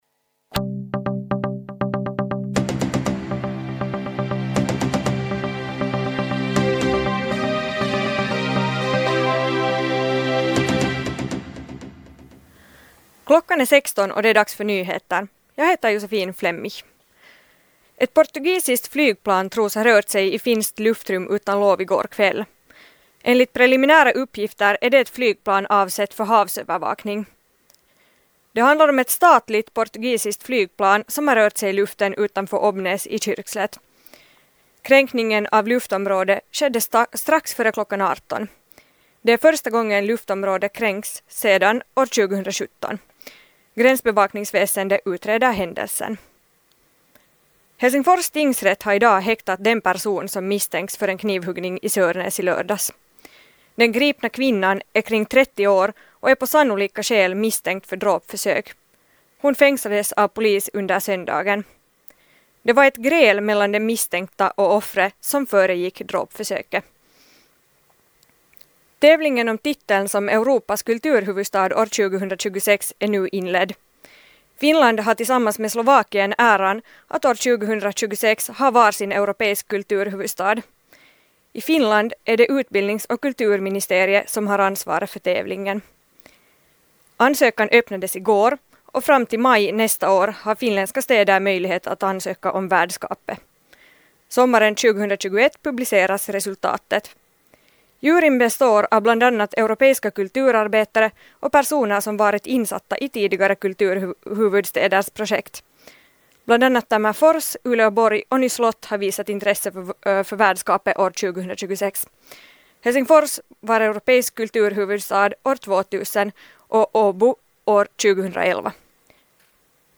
Radionyheter 2.4.2019 kl. 16 - Smocka